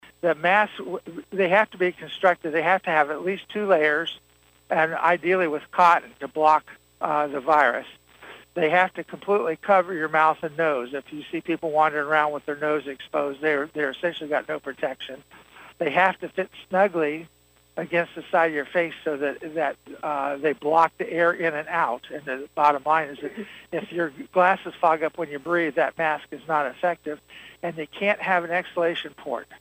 Medical Director Doctor Lauren Vogel from the Branch-Hillsdale-St. Joseph Community Health Agency told the Board of Health last Thursday using double masks offers no benefit when the single mask is used correctly.